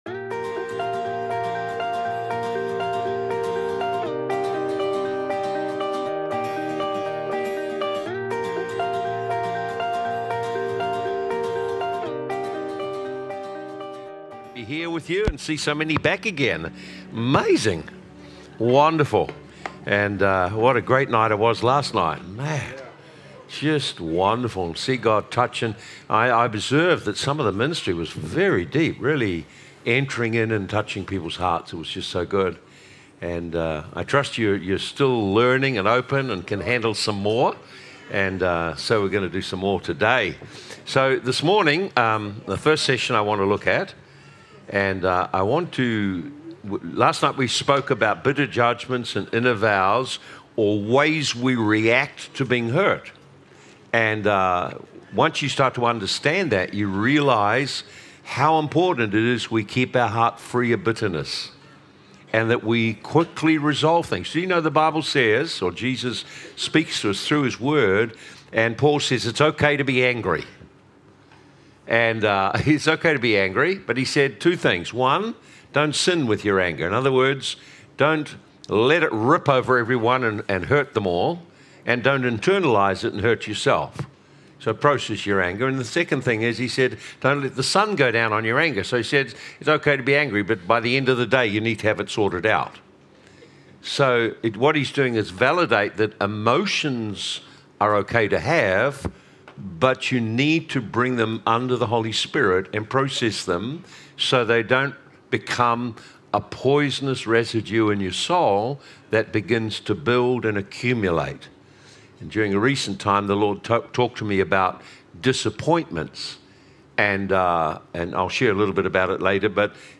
Recorded at Living Waters Christian Centre